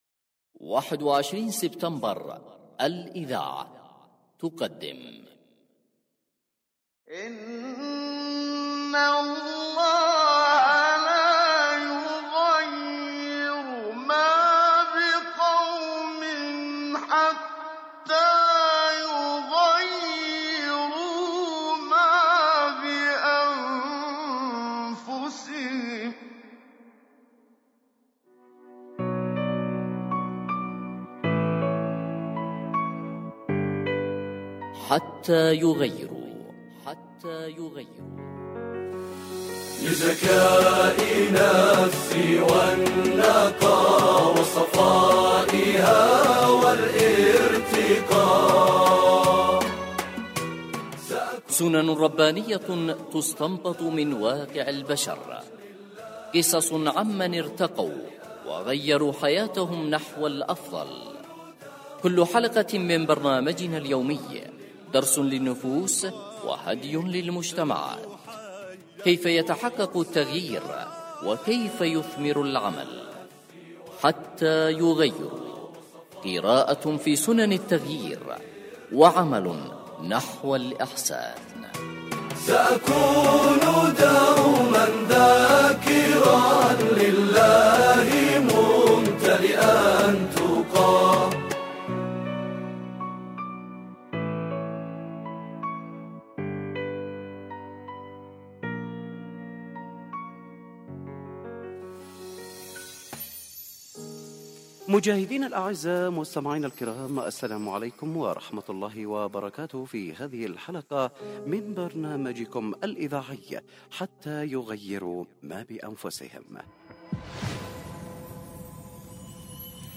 الحلقة الأولى (1)حتى يغيروا ما بأنفسهم، برنامج إذاعي يتطرق الى سنن الله في التغيير من خلال تغيير النفوس وارتباط ذلك بسنة الله في التغيير الذي لا يحابي أحد ولا يجامل أحد، مع التطرق الى النصوص القرآنية والنصوص التي قدمها اعلام الهدى في المشروع القرآني.